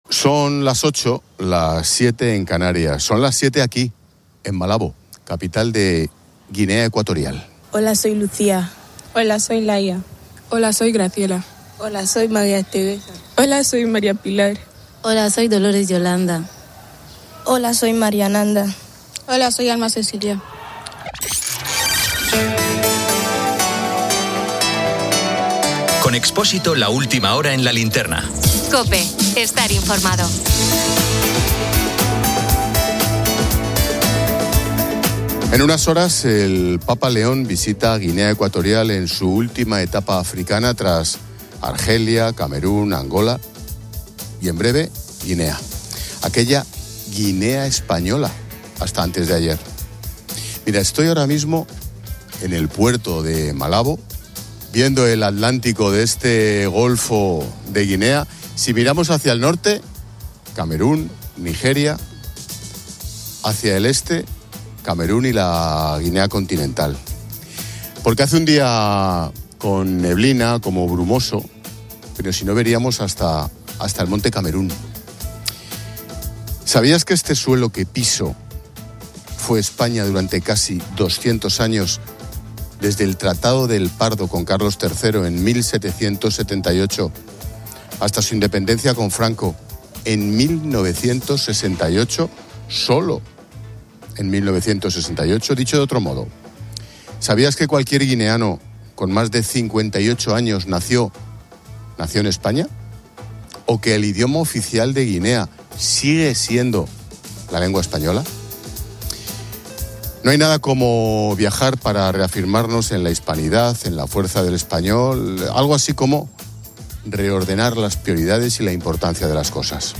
El programa se emite desde Malabo, Guinea Ecuatorial, destacando la historia del país como ex-colonia española y la próxima visita del Papa León XIV, que busca fortalecer la fe y la unidad católica. Se subraya la labor de misioneros españoles en la educación y el desarrollo guineano.